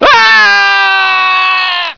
scream04.ogg